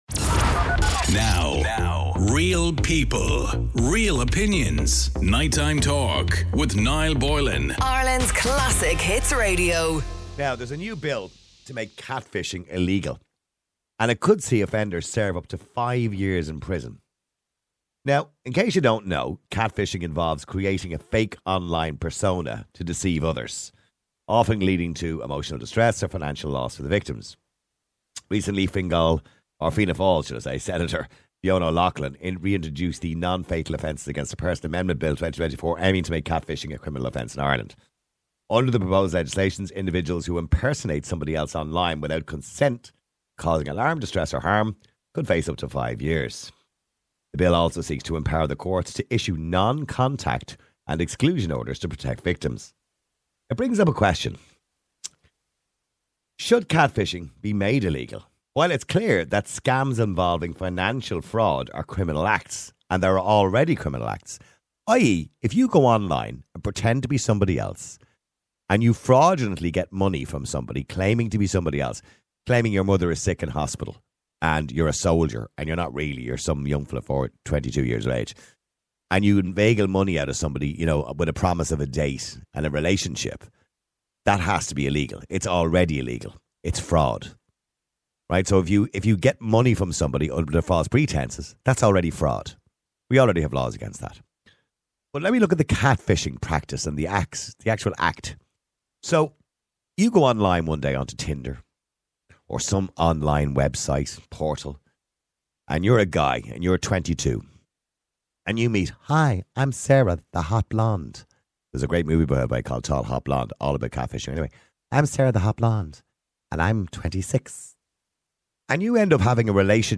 NIGHTTIME TALK - a radio talk show that cares about what you think!